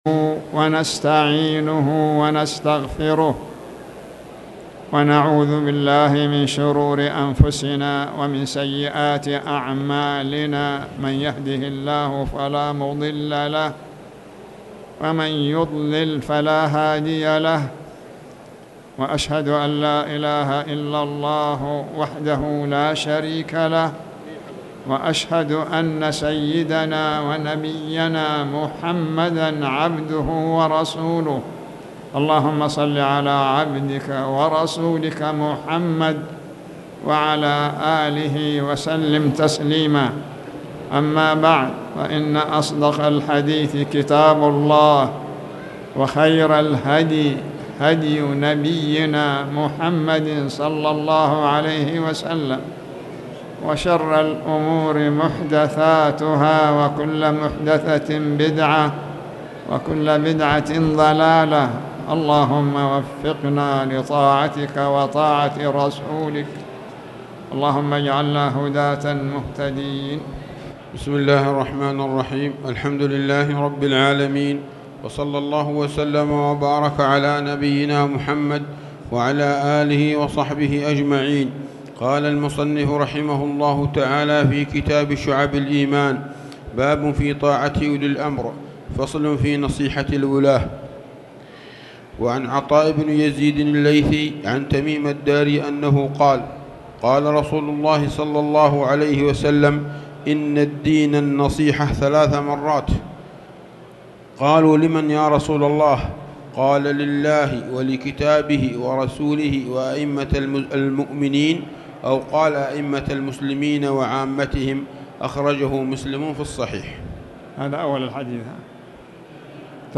تاريخ النشر ٣ محرم ١٤٣٨ هـ المكان: المسجد الحرام الشيخ